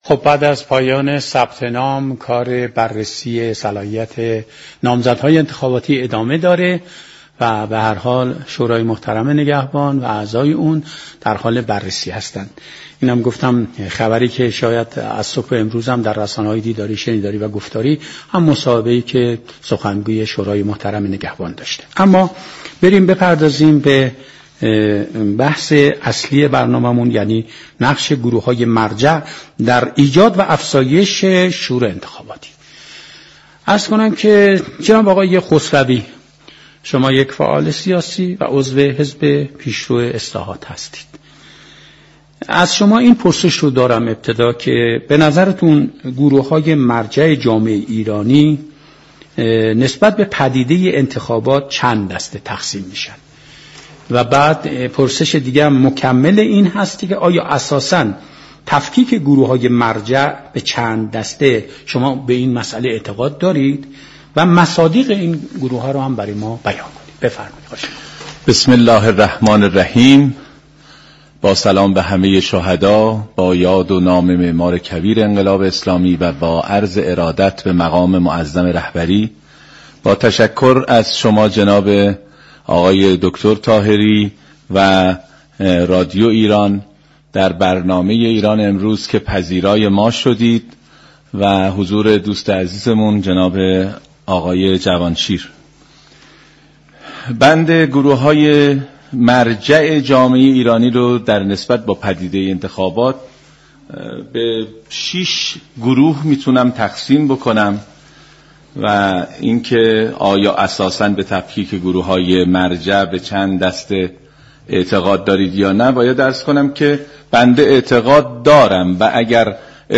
در ادامه این گفت و گوی رادیویی ایران،